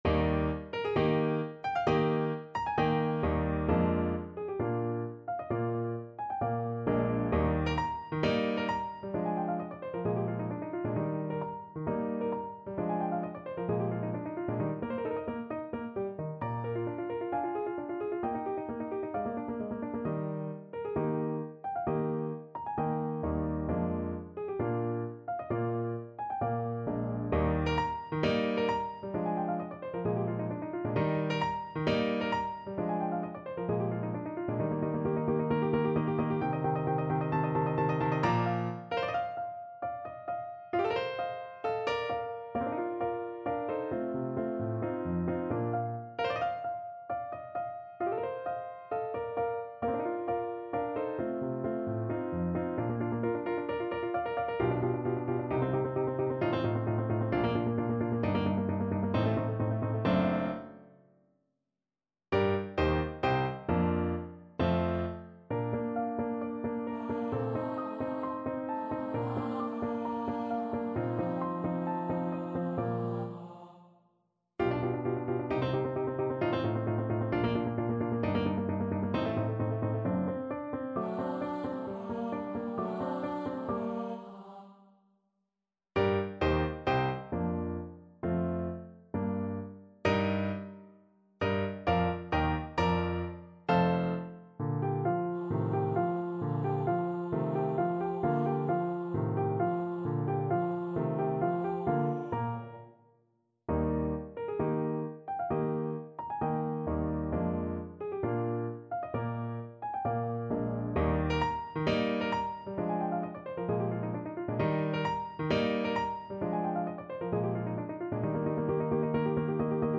Eb major (Sounding Pitch) (View more Eb major Music for Soprano Voice )
~ = 132 Allegro (View more music marked Allegro)
4/4 (View more 4/4 Music)
Classical (View more Classical Soprano Voice Music)